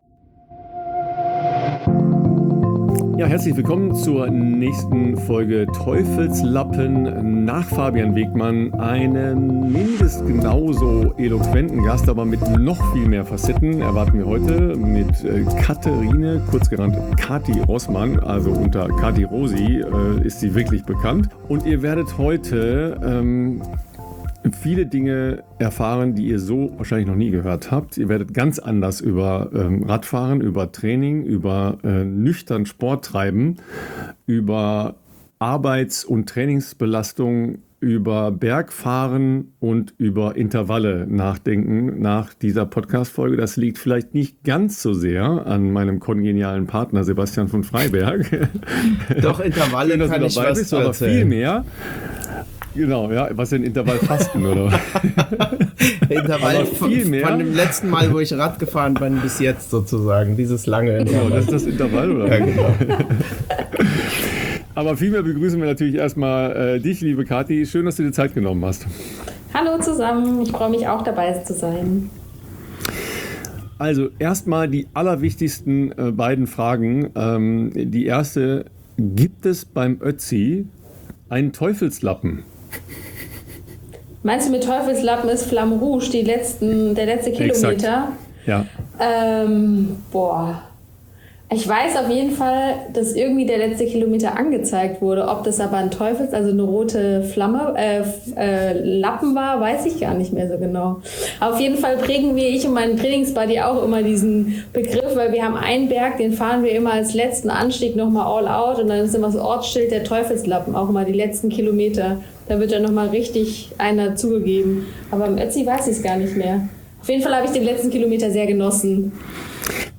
Entspannte Unterhaltung über alles rund um die professionelle Radsportszene.